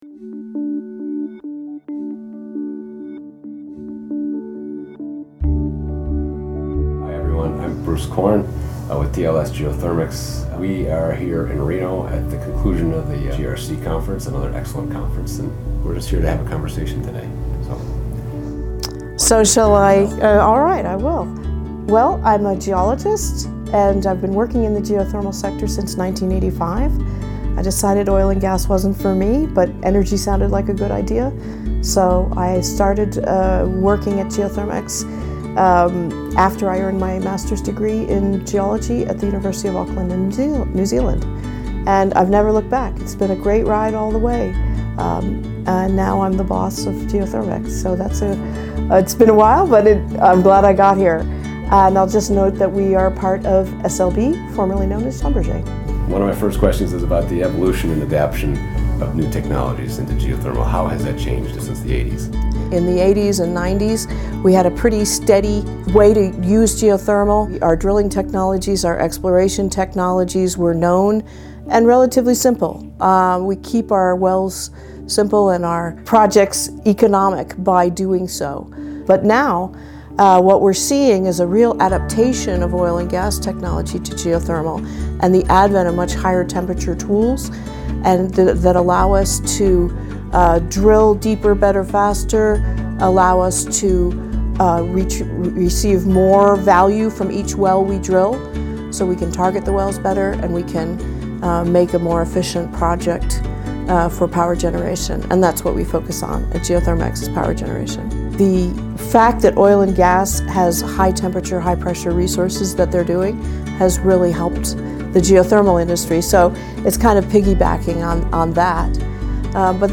We’re thrilled to announce the launch of our ‘Profound People’ conversation series – an intimate space for sharing wisdom and insights from luminaries and leaders of our Geothermal community.
We are here in Reno at the conclusion of the GRC conference, another excellent conference this year.